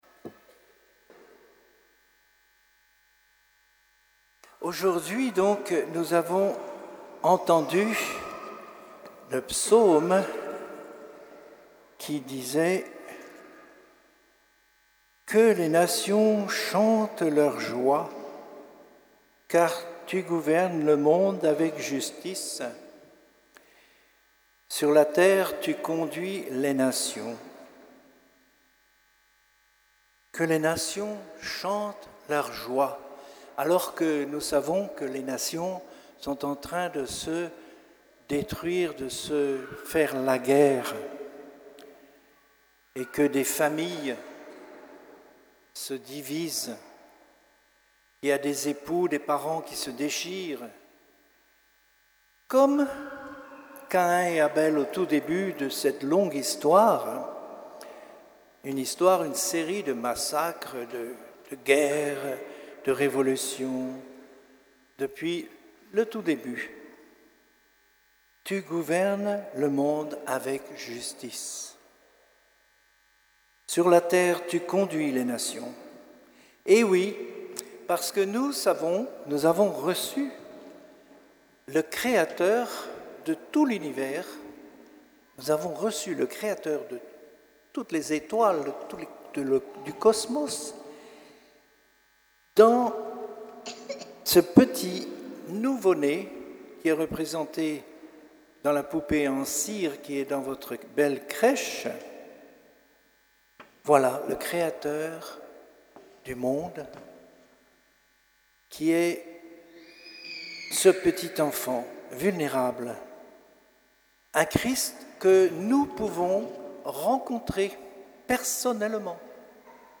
Homélie du temps de Noël 2022 et du 1er janvier 2023